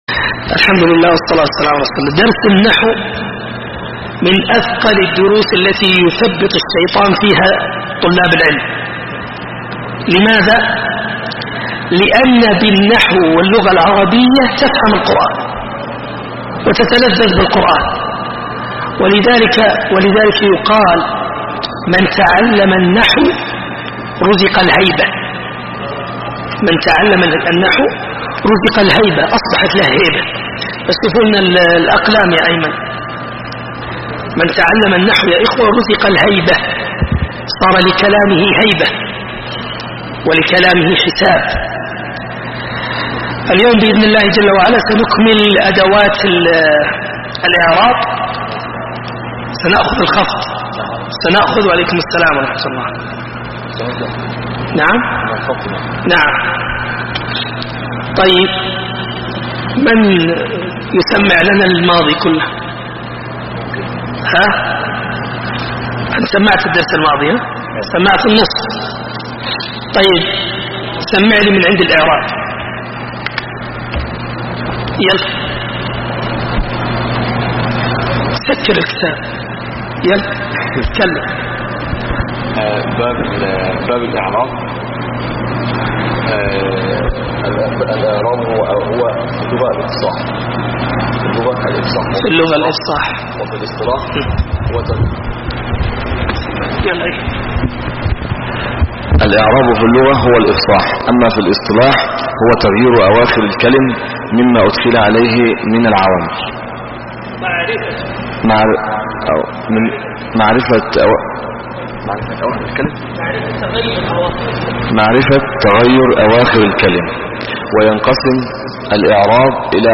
دروس وسلاسل